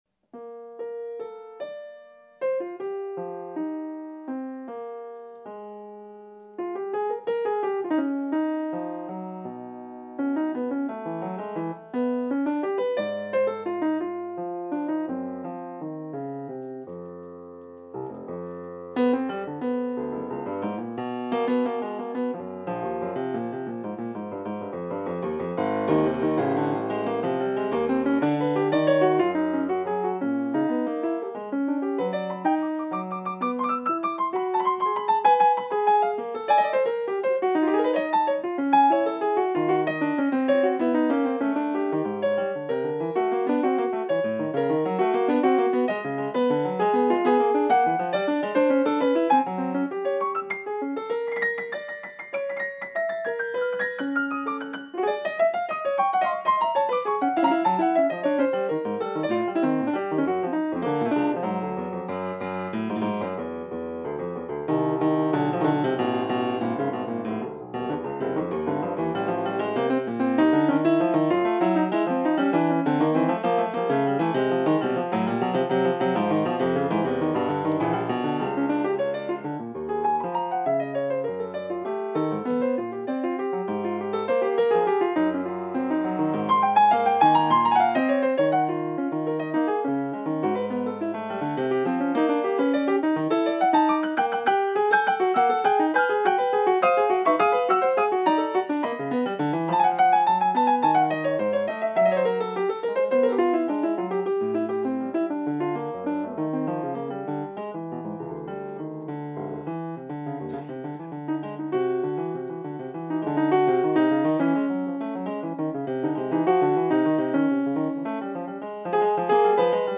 et une suite de douze pièces improvisées en concert en 2007: